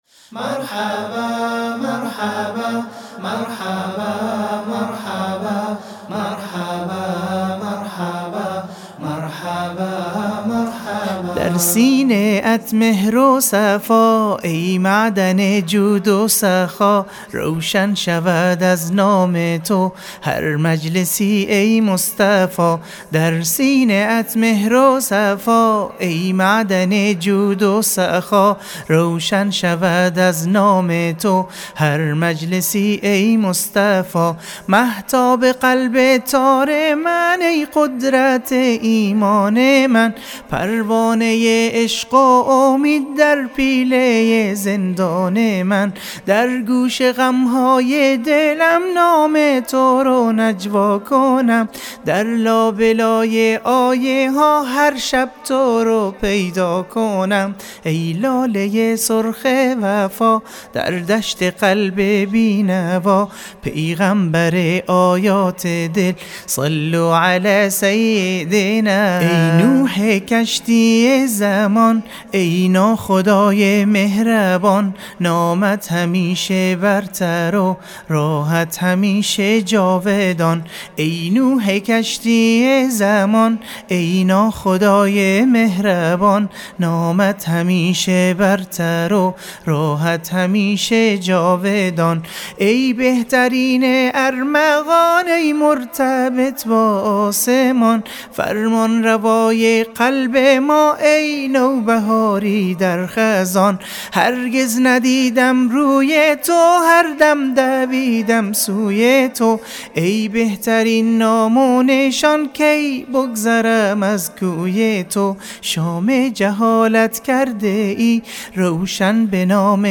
نشید